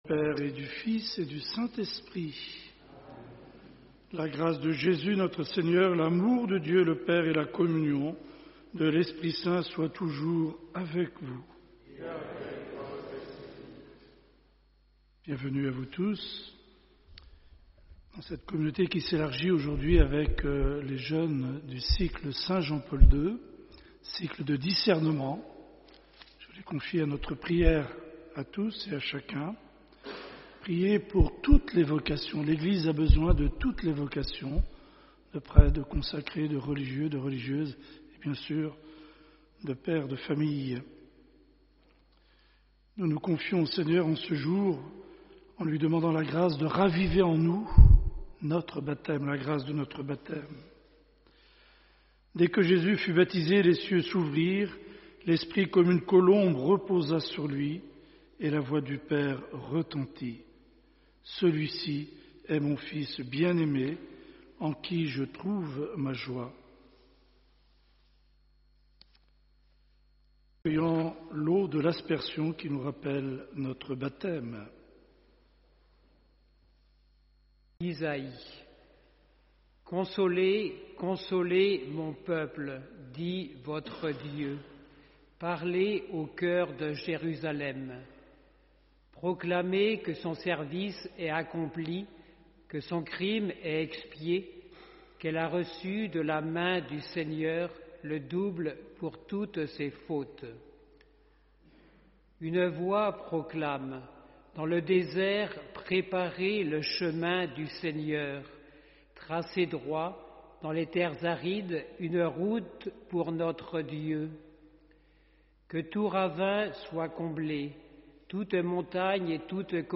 Homélie Chers pères, chers frères et sœurs, chers jeunes, nous célébrons en ce jour la fête du Baptême de Notre Seigneur Jésus-Christ.